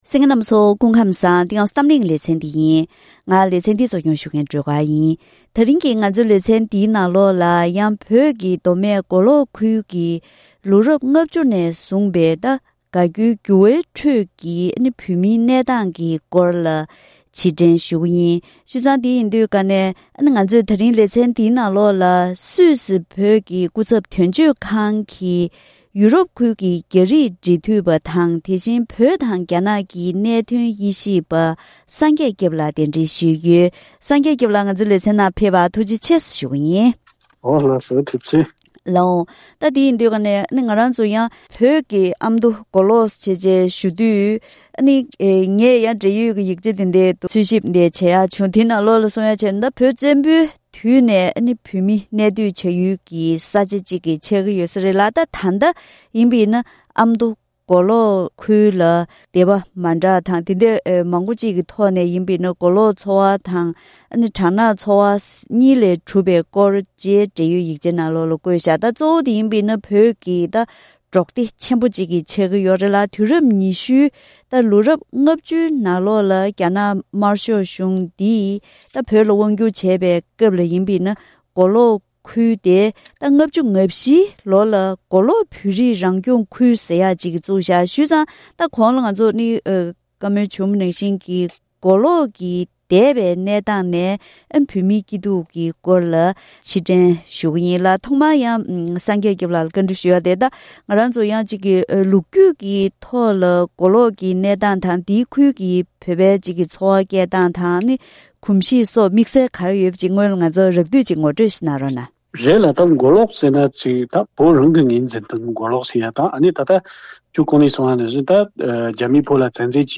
ད་རིང་གཏམ་གླེང་ལེ་ཚན་ནང་བོད་ཀྱི་མདོ་སྨད་མགོ་ལོག་ཁུལ་དུ་རྒྱ་ནག་དམར་ཤོག་གིས་རང་སྐྱོང་ཁུལ་བཙུགས་ནས་ལོ་ངོ་༧༠ཕྱིན་པའི་རྟེན་འབྲེལ་མཛད་སྒོ་ཞིག་འཚོགས་ཡོད་པས། ལོ་རབས་ལྔ་བཅུ་ནས་བཟུང་པའི་དགའ་སྐྱོའི་འགྱུར་བའི་ཁྲོད་ནས་མགོ་ལོག་གི་བོད་མིའི་གནས་སྟངས་སྐོར་ལ་ཕྱིར་དྲན་ཞུས་པ་ཞིག་གསན་རོགས་གནང་།